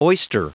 Prononciation du mot oyster en anglais (fichier audio)
Prononciation du mot : oyster